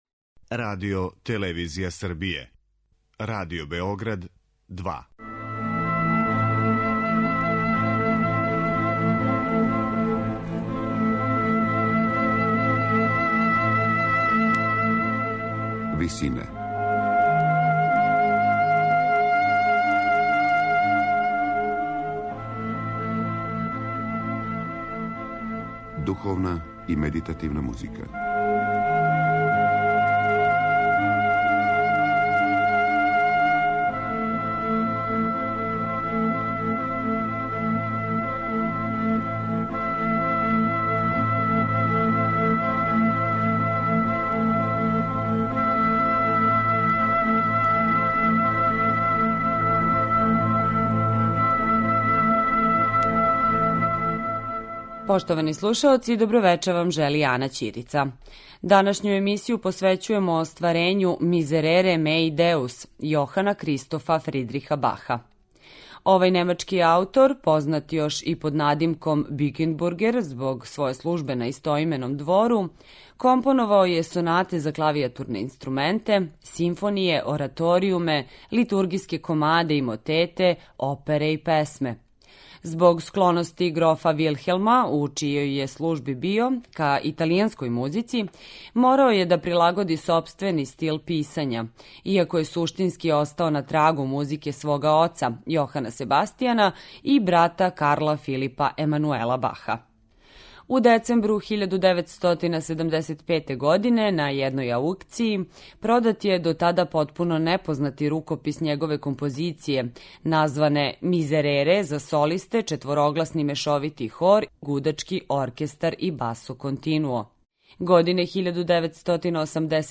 На крају програма, у ВИСИНАМА представљамо медитативне и духовне композиције аутора свих конфесија и епоха.
Прву овонедељну емисију посвећујемо остварењу које је за солисте, хор и гудаче, а на стихове Псалама 50 и 51, компоновао Јохан Кристоф Фридрих Бах.